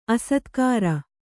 ♪ asatkāra